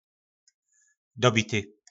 Slovník nářečí Po našimu
Unavený - Dobity